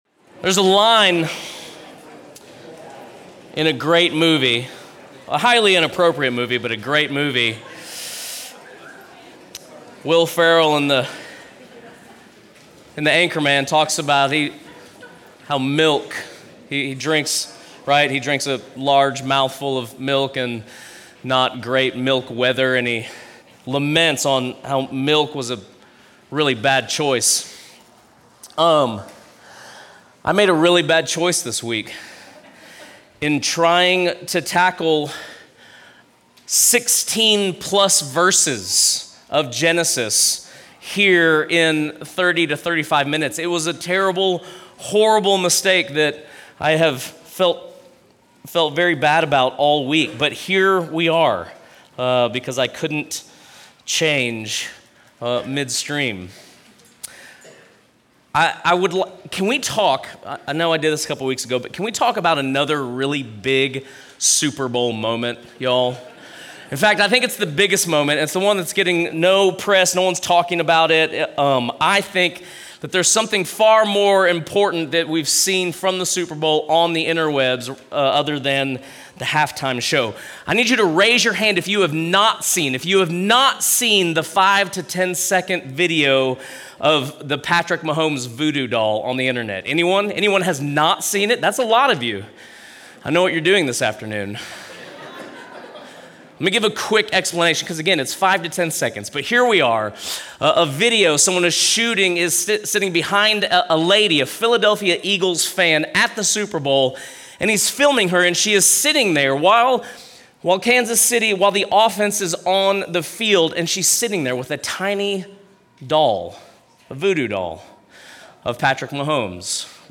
Sunday Worship | Substance Church, Ashland, Ohio
Sermons